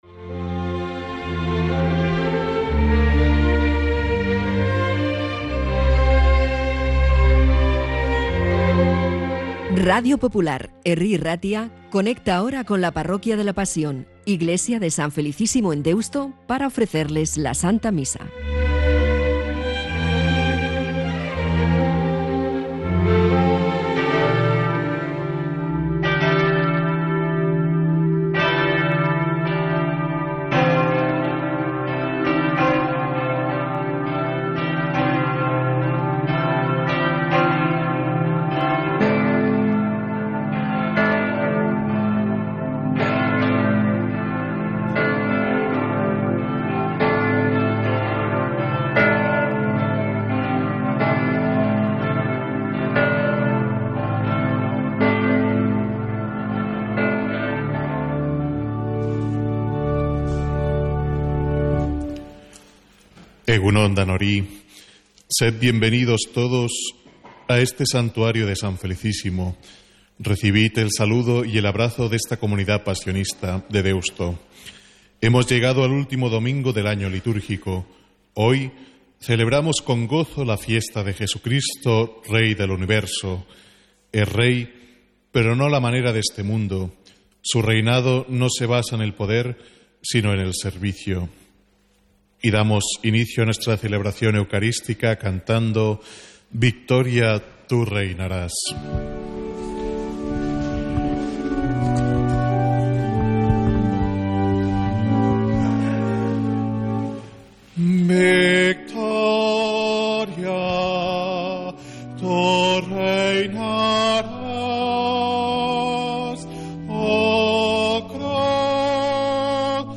Santa Misa desde San Felicísimo en Deusto, domingo 24 de noviembre